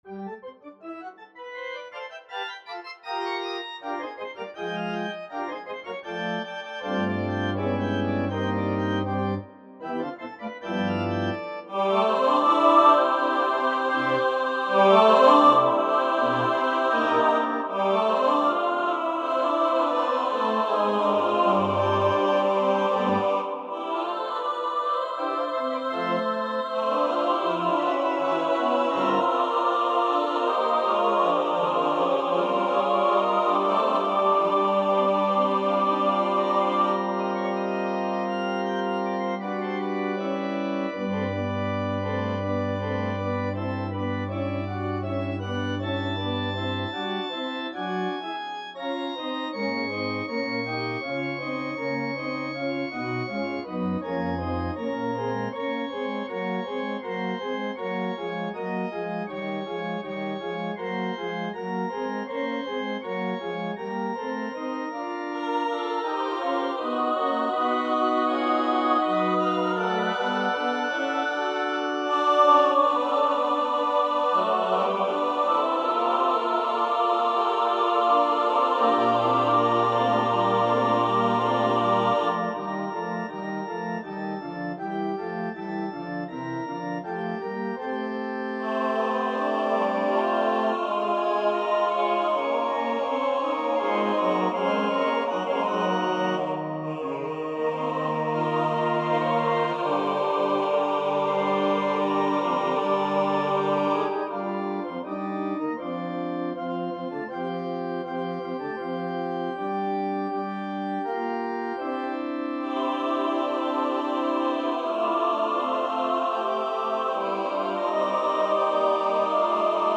• Music Type: Choral
• Voicing: SATB
• Accompaniment: Organ